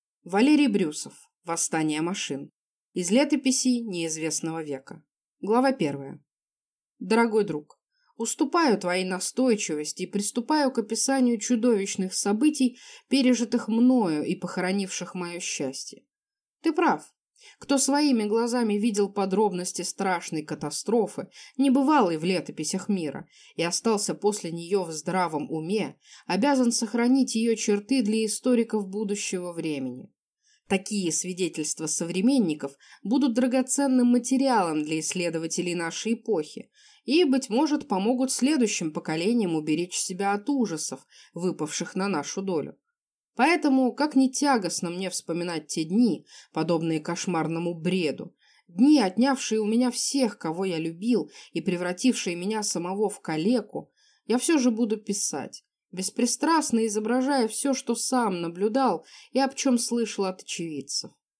Аудиокнига Восстание машин | Библиотека аудиокниг